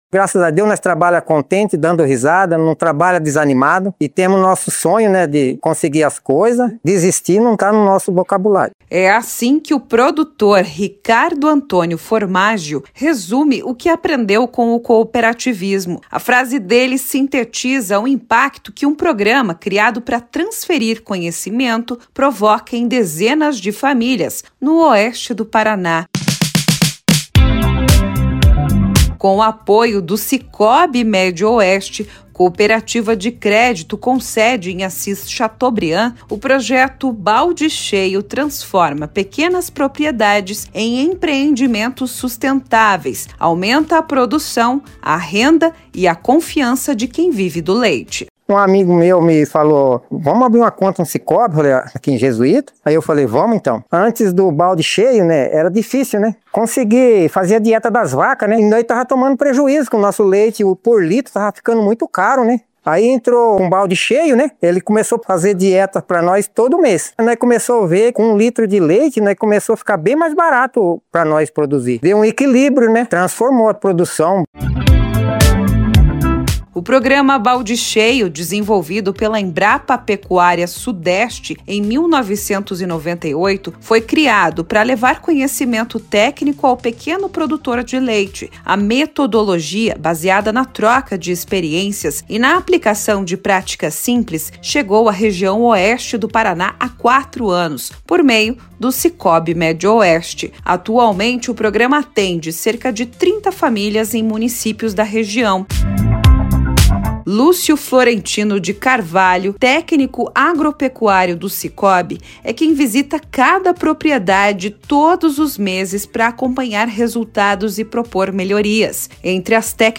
Reportagem-2-Baldes-cheios-vidas-renovadas.mp3